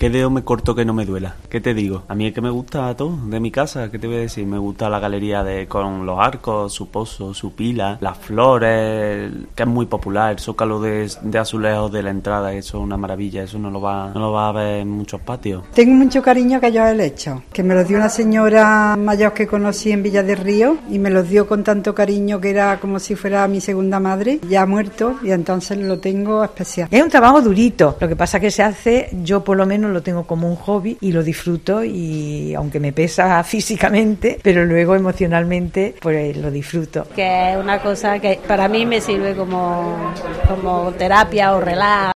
Escucha a algunos cuidadores de patios del recorrido de Santa Marina